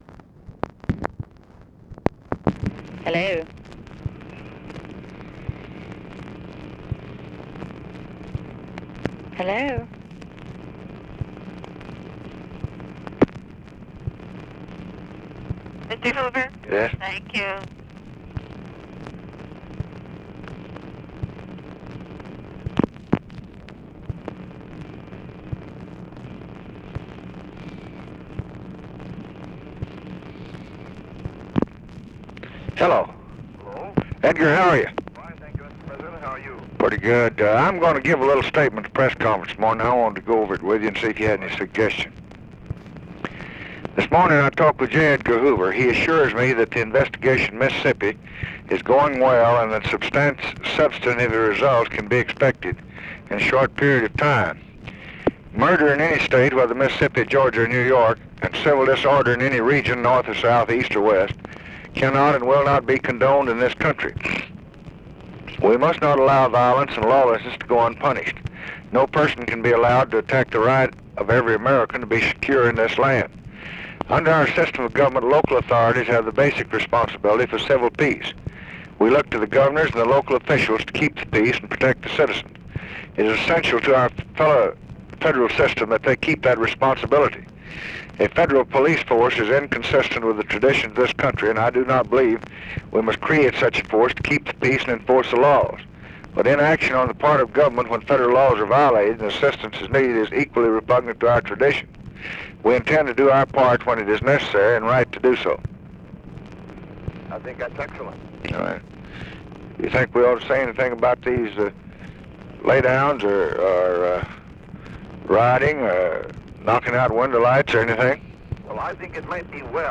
Conversation with J. EDGAR HOOVER, August 8, 1964
Secret White House Tapes